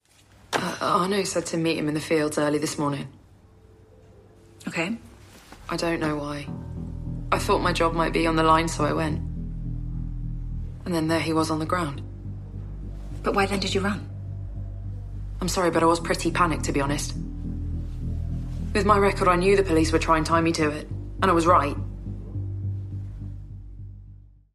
English dubbing by VSI London